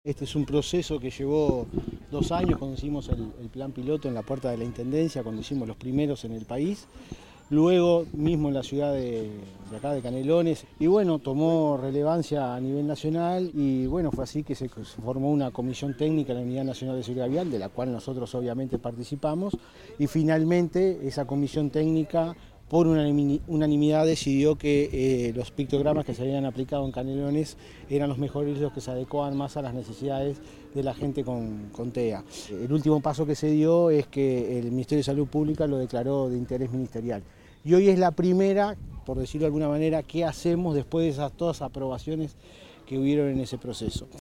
Por su parte, el Director de Ingeniería de Tránsito del Gobierno de Canelones, Adrián Bringa, expresó que es un proceso que llevó dos años y que tomó “relevancia a nivel nacional gracias a que las organizaciones sociales fueron las que empujaron la propuesta”. A su vez, destacó como un dato no menor que “intervinieron los tres niveles de Gobierno, sin banderas políticas, y aun así hubo consenso”.